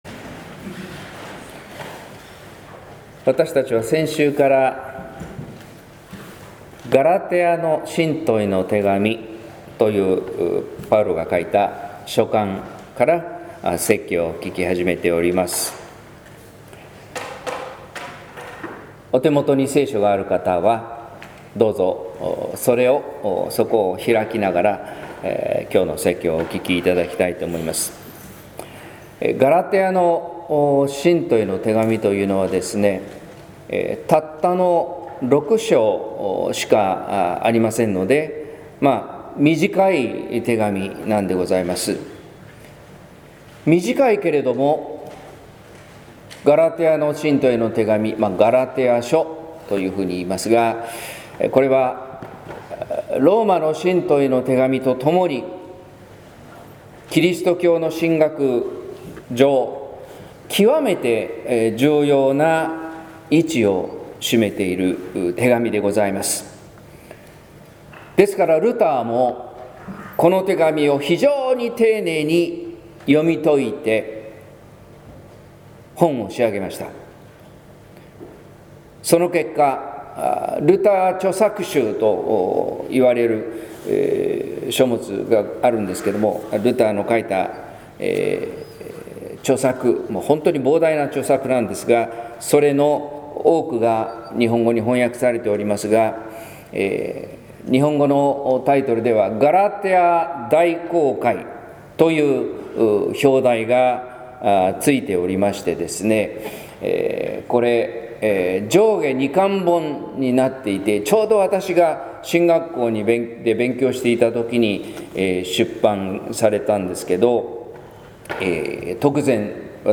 説教「イエスの真実」（音声版）